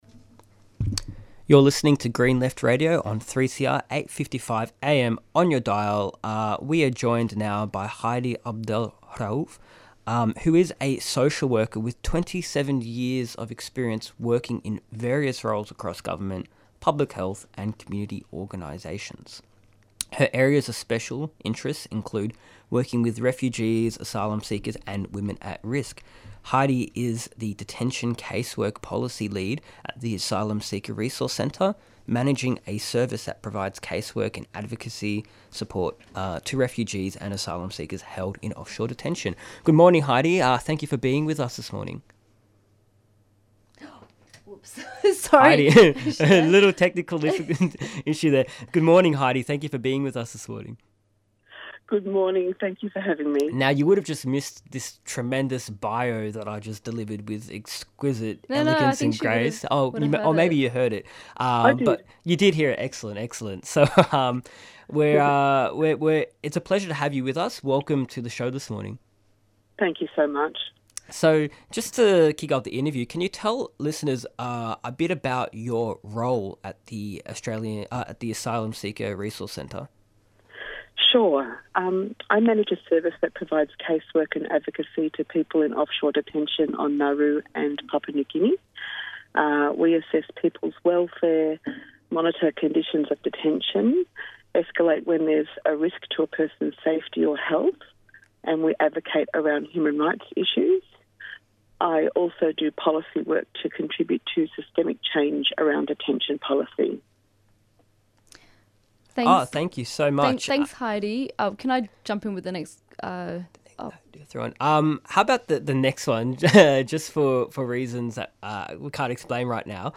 Newsreports Presenters discuss some of the latest political developments and issues including Federal Elections on May 3 - Election 2025: Voters reject ‘Trump-lite’ but will Labor deliver?
Interviews and Discussion